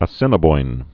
(ə-sĭnə-boin)